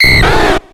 Cri de Minidraco dans Pokémon X et Y.